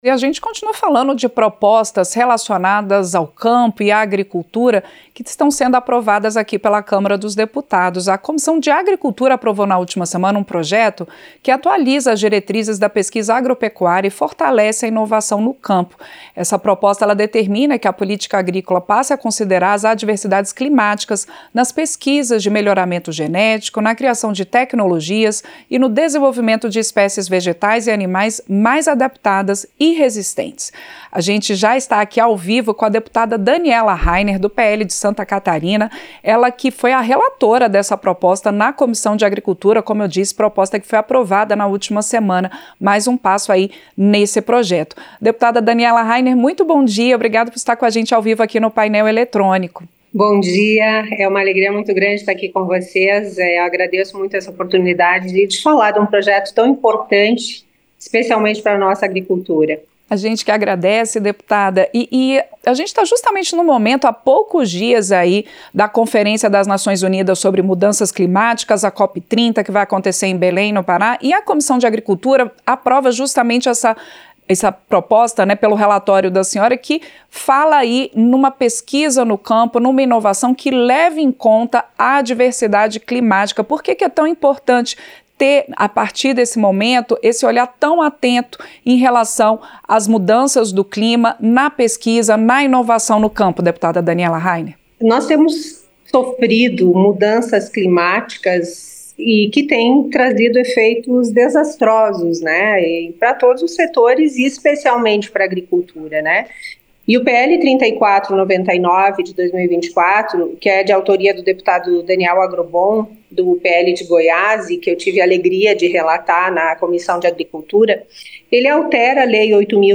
Entrevista - Dep. Daniela Reinehr (PL-SC)